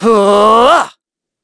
Evan-Vox_Casting1.wav